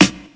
• Rich Top End Rap Snare Drum Sample F# Key 165.wav
Royality free snare drum sample tuned to the F# note. Loudest frequency: 2153Hz
rich-top-end-rap-snare-drum-sample-f-sharp-key-165-yvz.wav